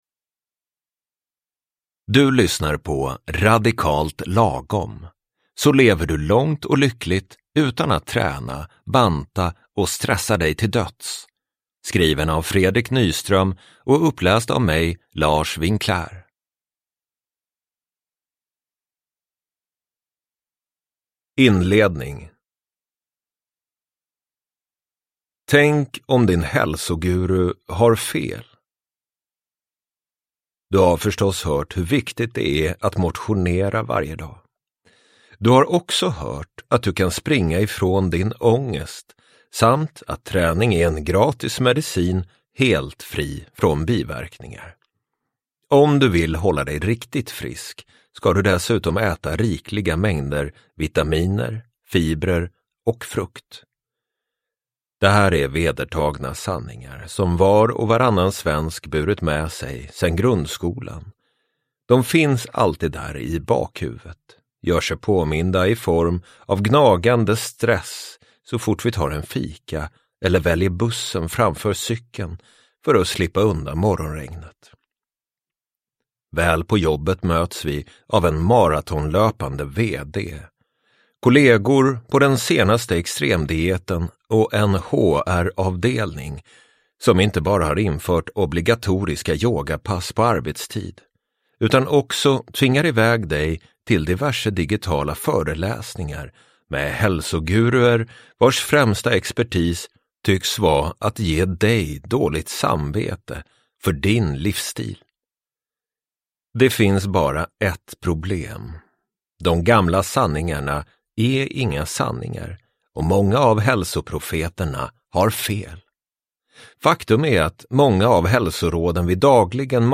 Radikalt lagom : Så lever du långt och lyckligt utan att träna, banta och stressa dig till döds – Ljudbok – Laddas ner